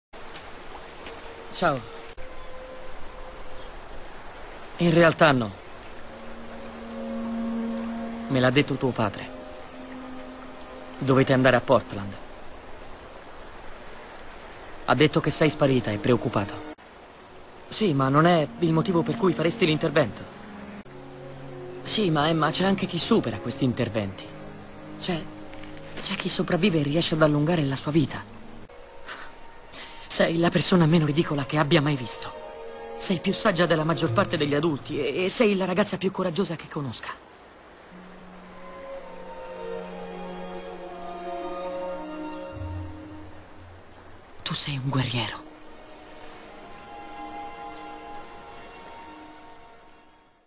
nel telefilm "Bates Motel", in cui doppia Max Thieriot.